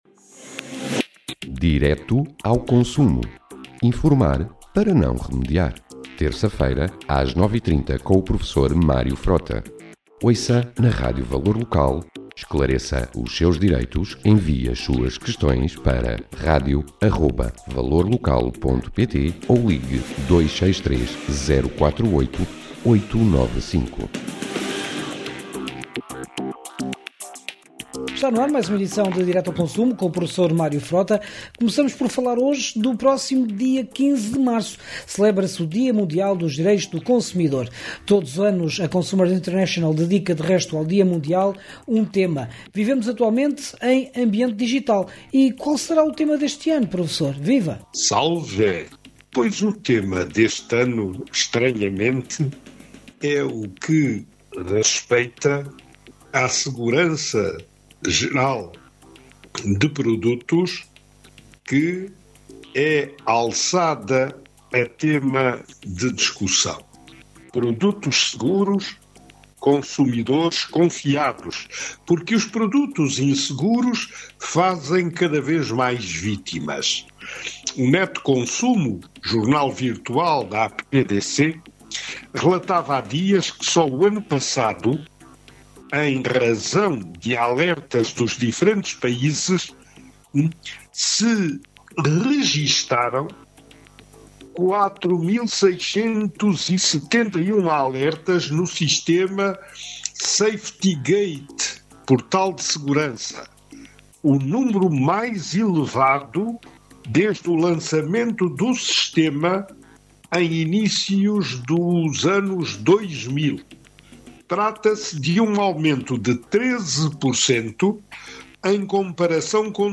Celebra-se no próximo domingo, 15 de Março, o dia Mundial dos Direitos do Consumidor. No programa de hoje o professor fala deste e de outros temas, destacando o nosso consultório com perguntas do auditõrio.